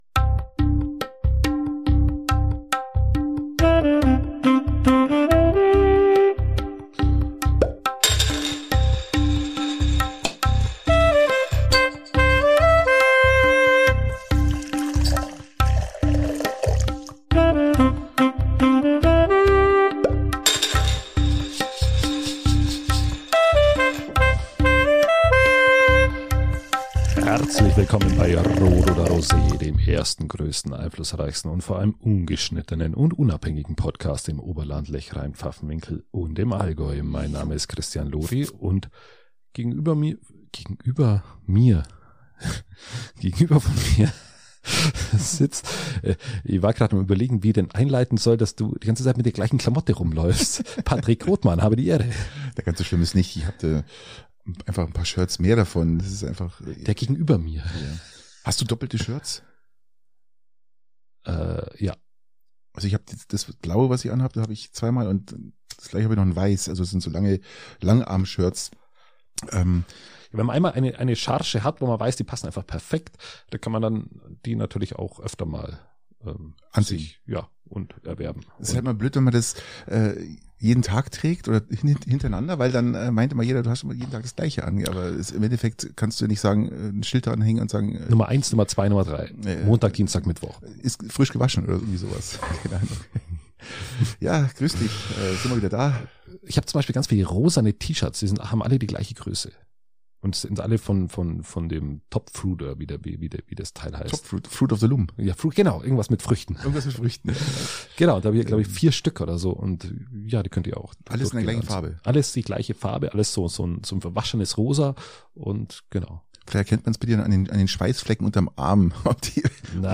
Ungeschnittenen und unabhängig!